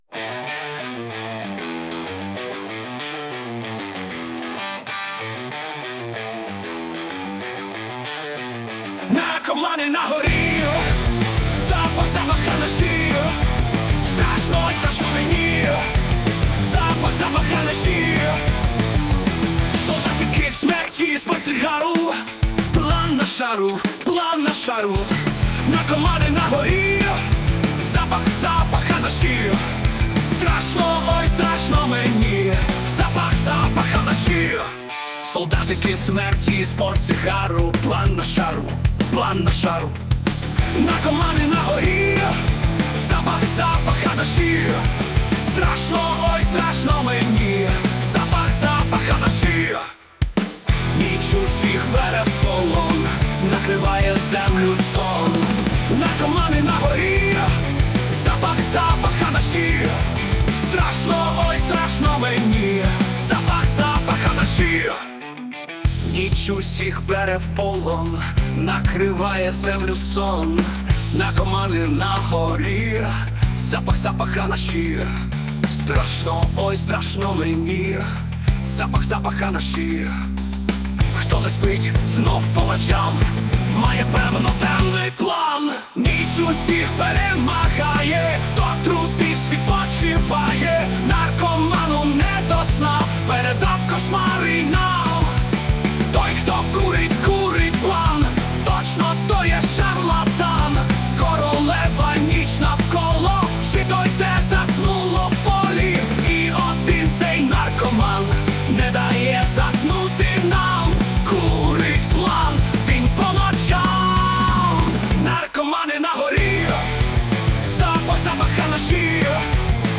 Але ж музика,як шквал хвиль морських...